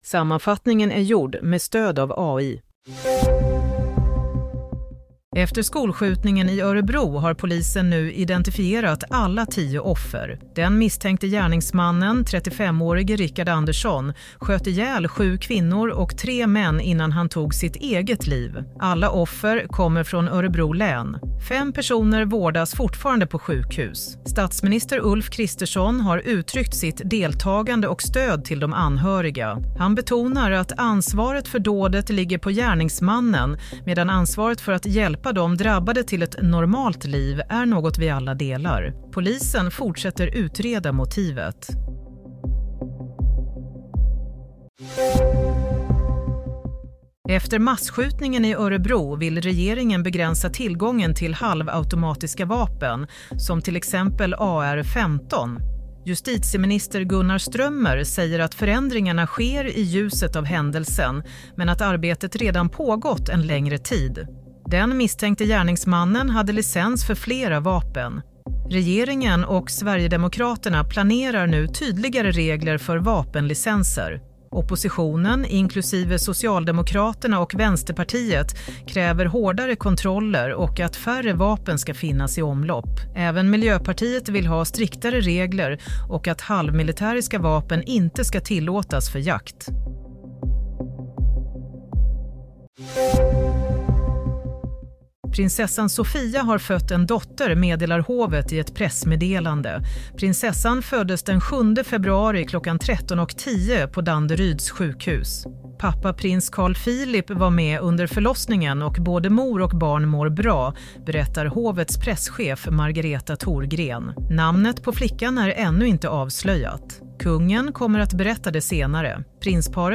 Nyhetssammanfattning – 7 februari 22:00